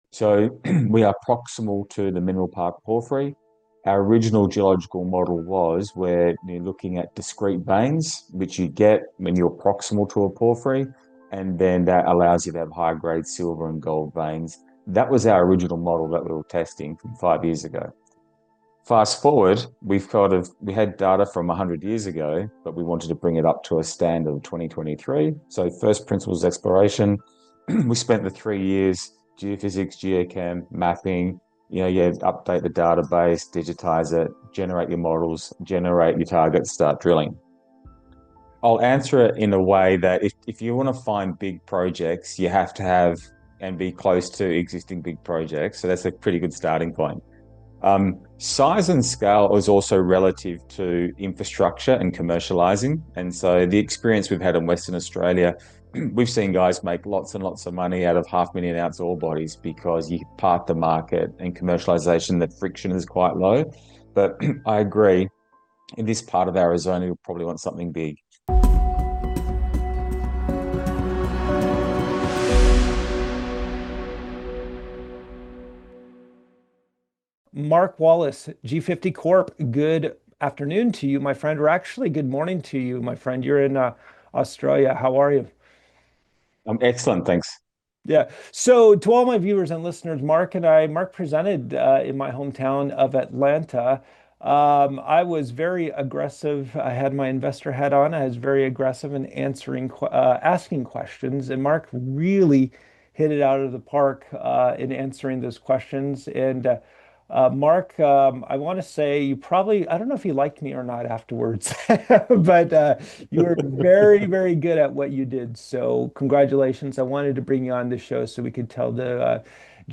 In this Natural Resource Stocks company interview